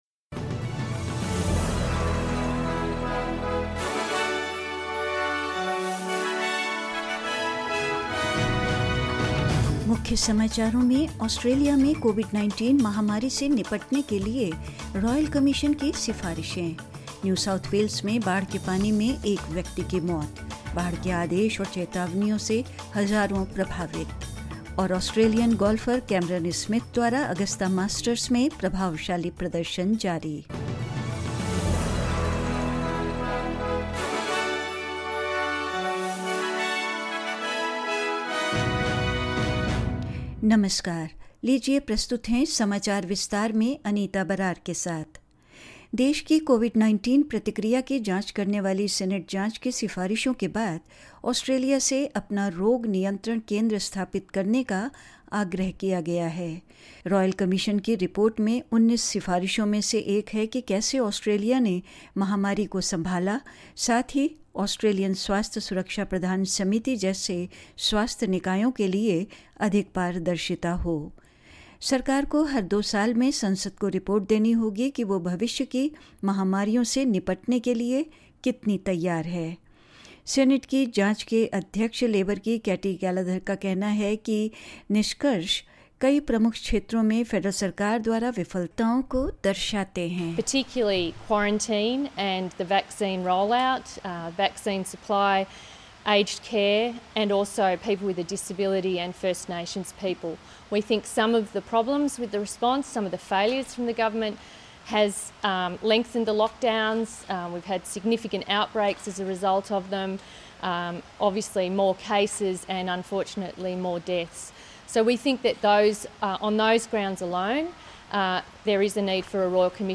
In this latest Hindi bulletin: Recommendations for a Royal Commission into Australia's handling of the Covid-19 pandemic; Thousands of holiday plans thrown into disarray following major delays at Sydney Airport; In sport, Australian golf star Cameron Smith continues his impressive run at the 2022 Augusta Masters and more news.